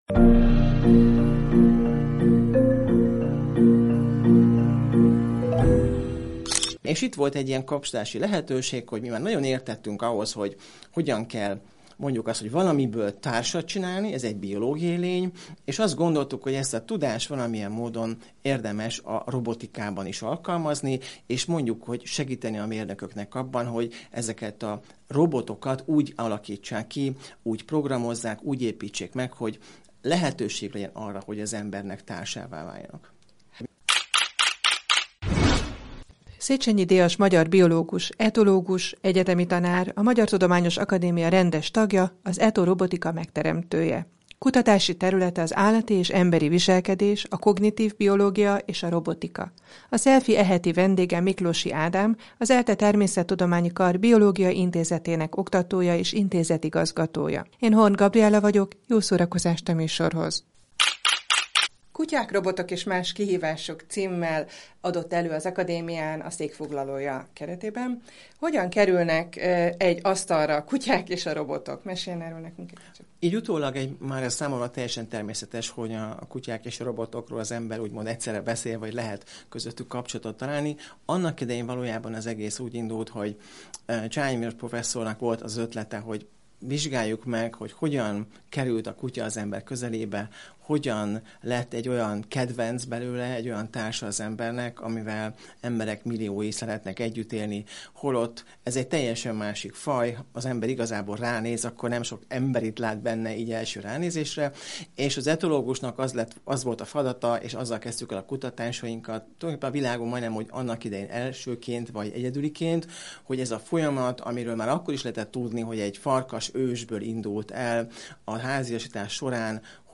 Akár 10-15 év múlva a hétköznapi életben is elterjedhetnek az embereket segítő társrobotok. Ezek gyártásához nemcsak mérnökök szükségesek, hanem például biológusok (etológusok), pszichológusok is – egyebek mellett erről beszélgettünk a Szabad Európa podcastjában.